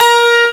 Index of /m8-backup/M8/Samples/Fairlight CMI/IIX/GUITARS